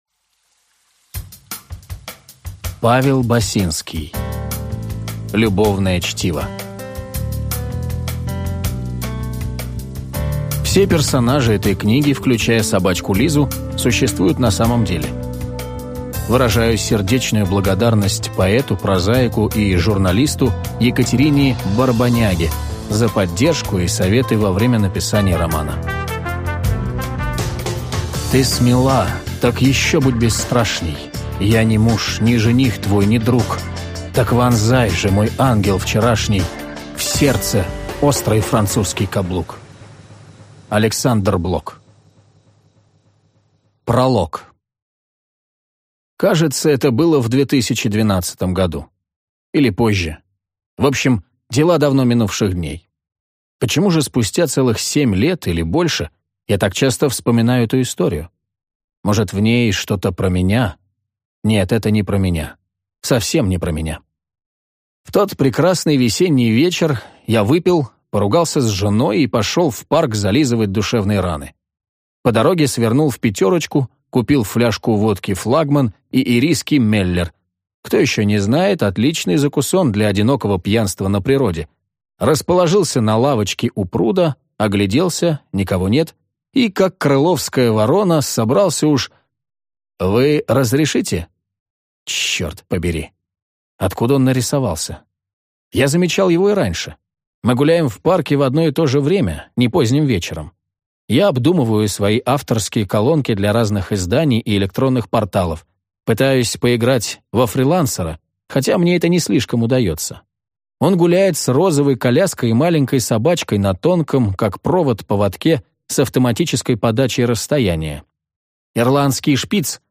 Аудиокнига Любовное чтиво | Библиотека аудиокниг